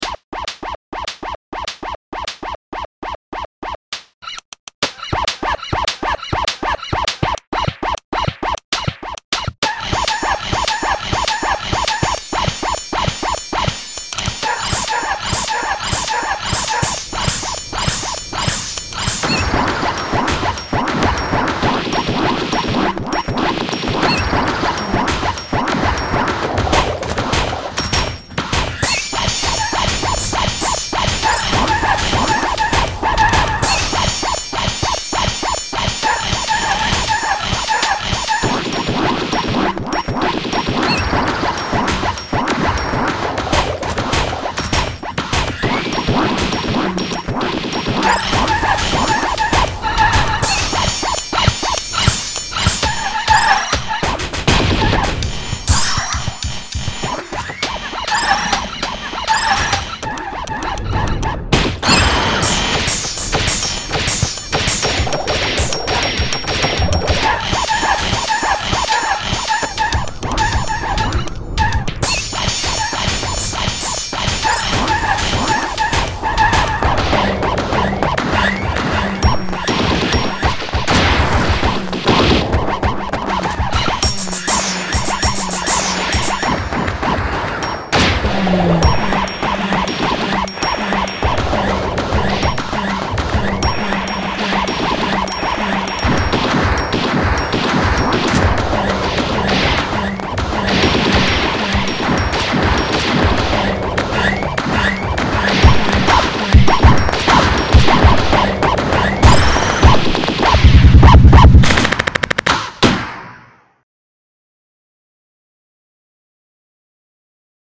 AMBIENT TECHNO MUSIC